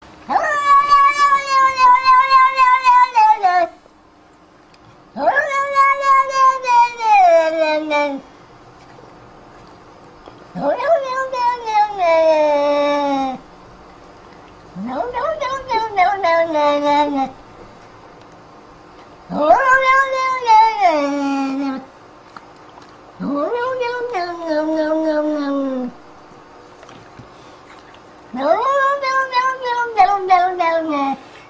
Звуки котов
Звук кот говорит No no no oh no oh no oh no no no no